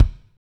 KIK M R K08L.wav